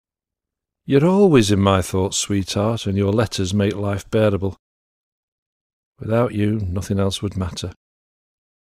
Soldier talks about his dependence on letters from home